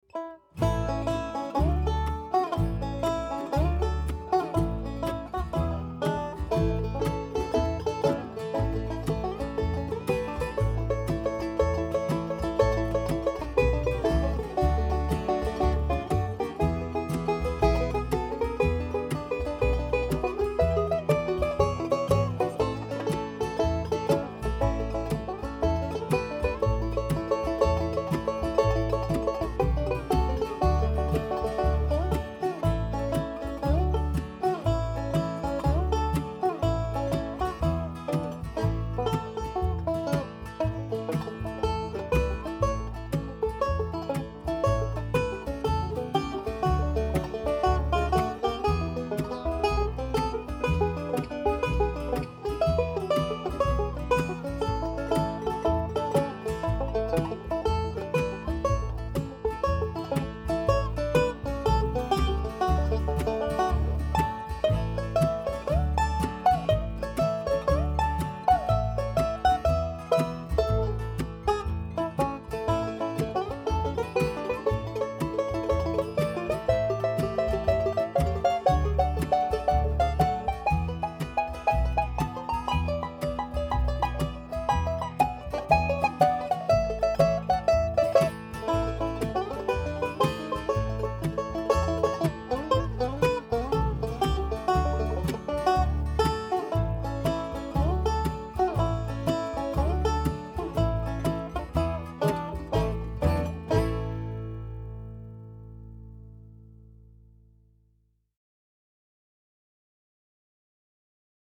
DIGITAL SHEET MUSIC - 5-STRING BANJO SOLO
Three-finger "Scruggs style"
learning speed and performing speed